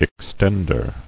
[ex·tend·er]